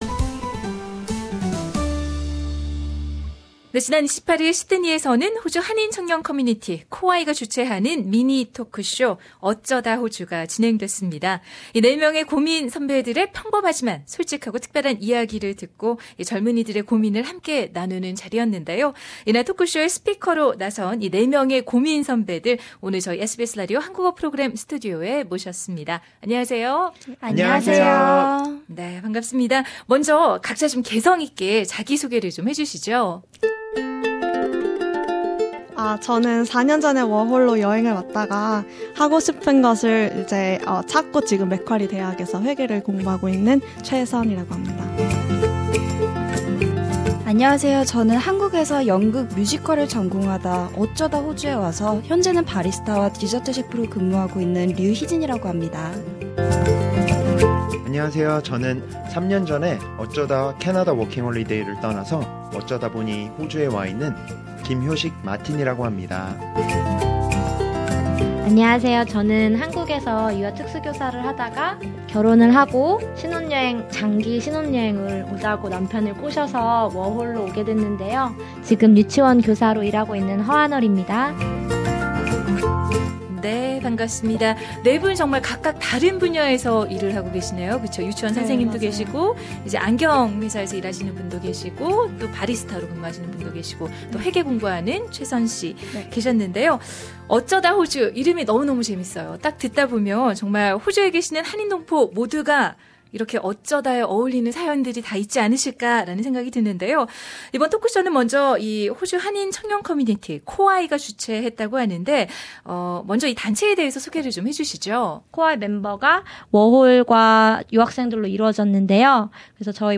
A full interview is available on Podcast above.